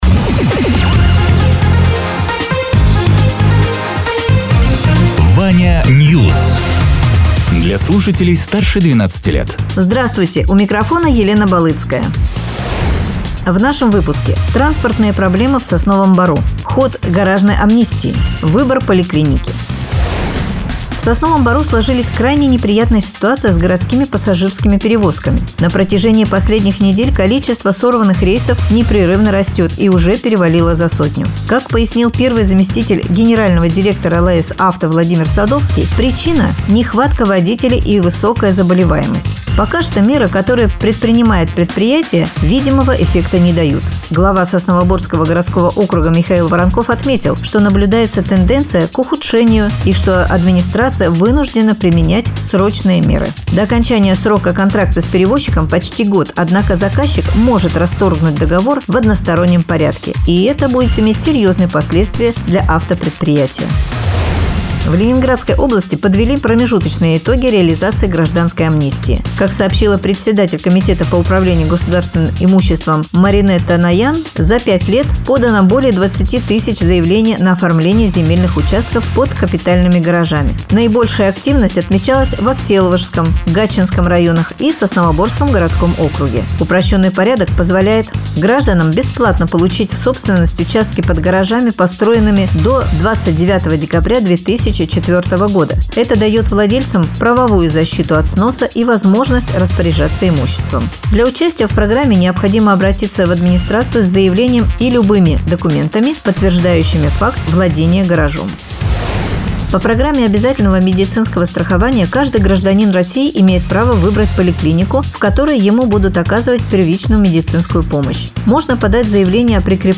Радио ТЕРА 01.04.2026_10.00_Новости_Соснового_Бора